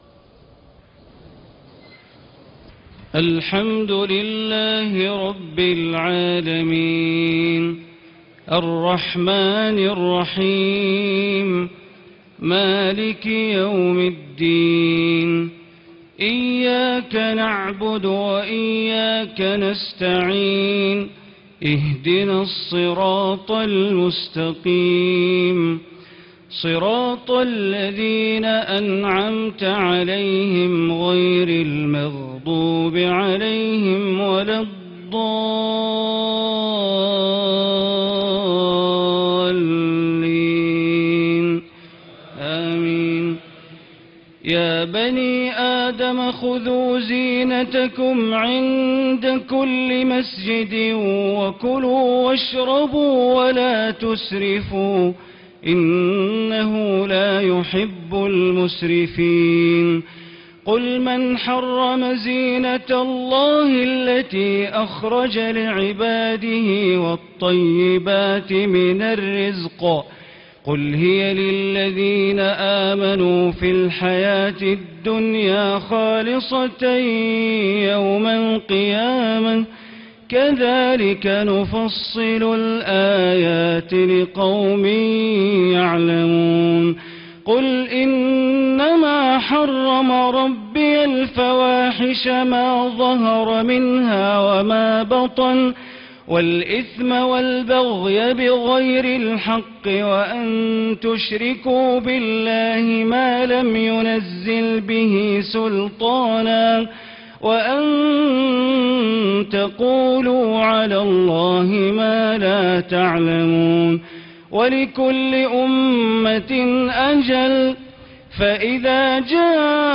تهجد ليلة 28 رمضان 1436هـ من سورة الأعراف (31-93) Tahajjud 28 st night Ramadan 1436H from Surah Al-A’raf > تراويح الحرم المكي عام 1436 🕋 > التراويح - تلاوات الحرمين